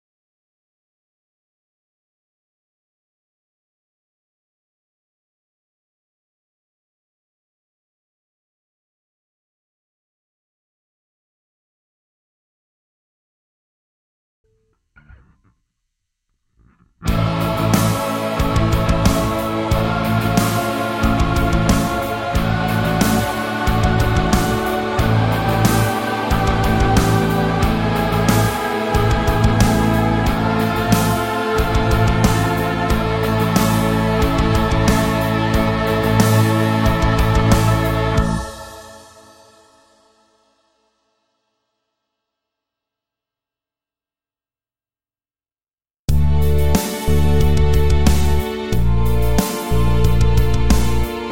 Despite that, the progression itself is "chaotic" in the sense that it has no clear tonal center or home base.
A progression where every chord contains the note A
Progression With A In Every Chord